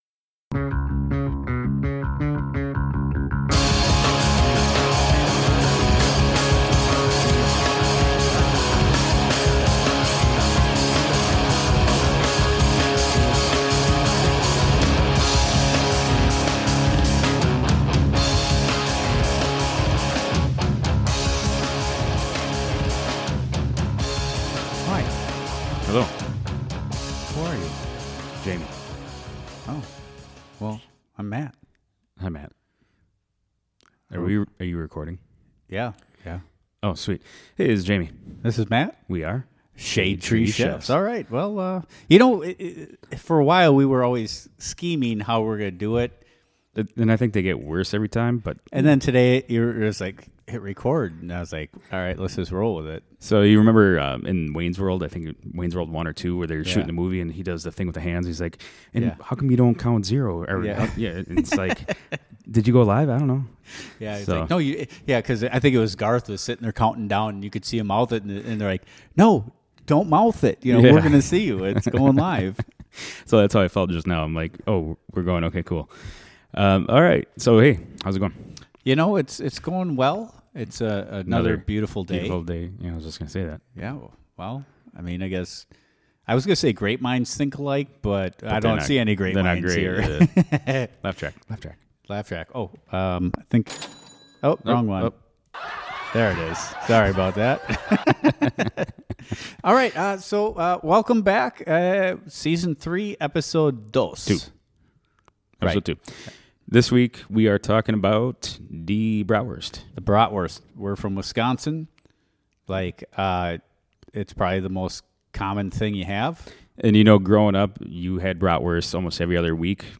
Two guys talking about what they love.